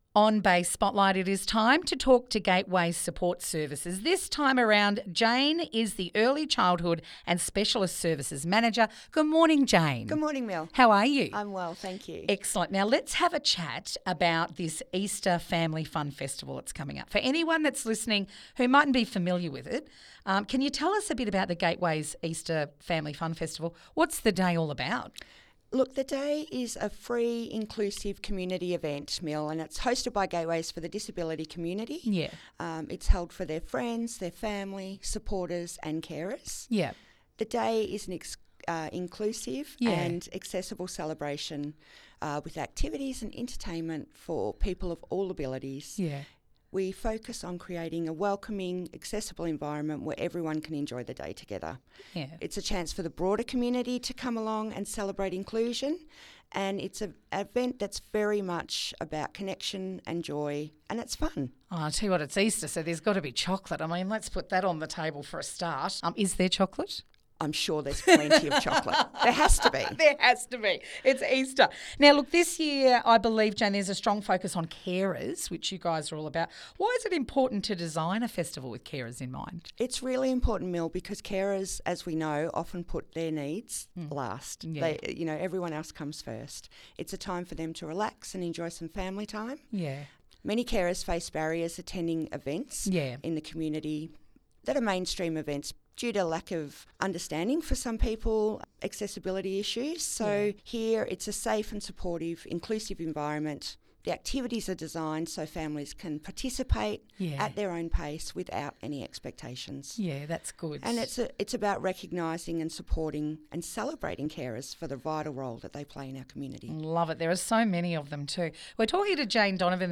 Bay Fm Interview (1)